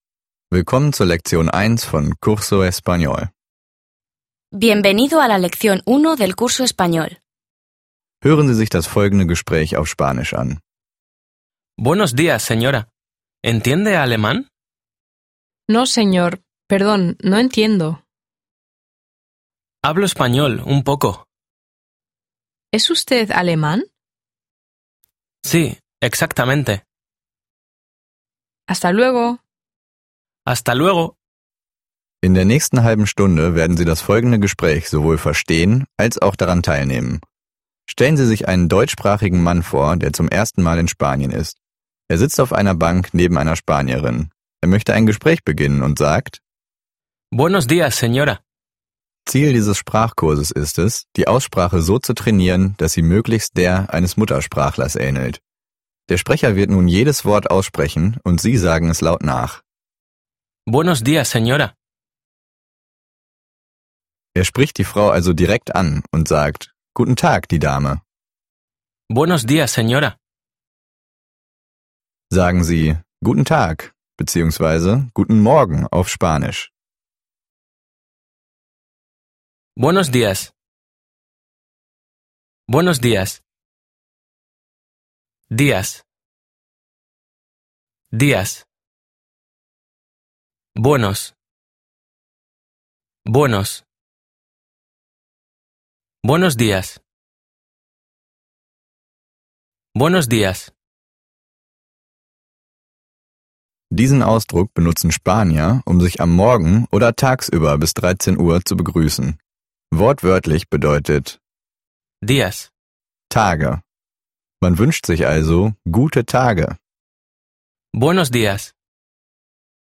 Es wird direkt ein kurzer Dialog vorgelesen, der danach Wort für Wort und Silbe für Silbe, in der 30-minütigen Lektion, wieder und wieder gesprochen wird. Dabei soll der Zuhörer auch aktiv mitmachen und die einzelnen Silben, Worte und Sätze nachsprechen.